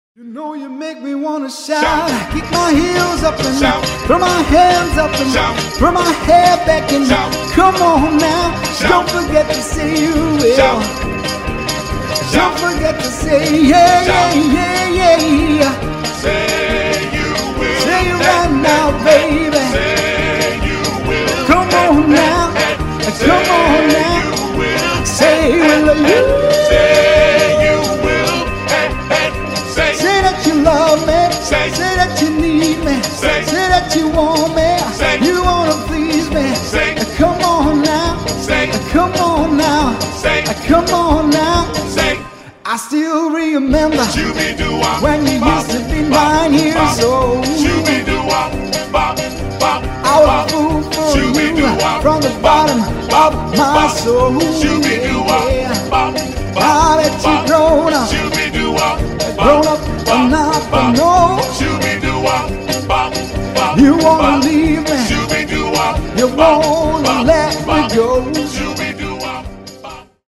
These are remastered live recordings from recent events.